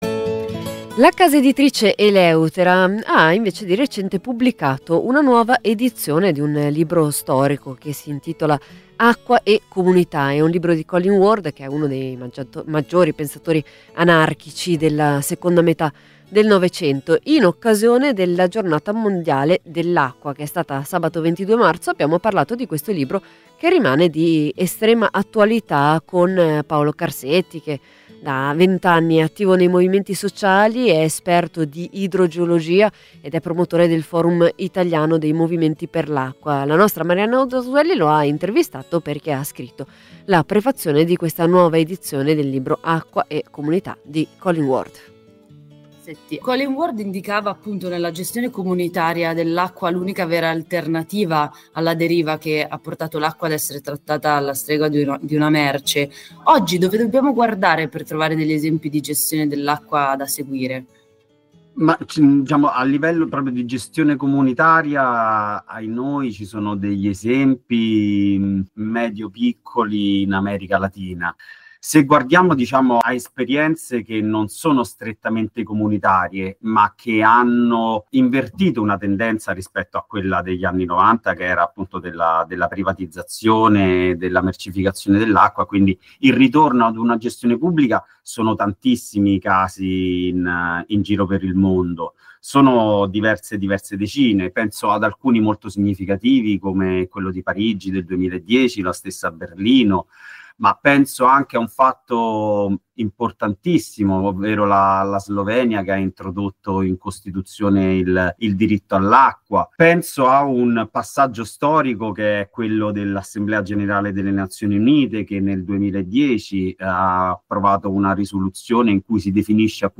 Acqua e comunità - intervista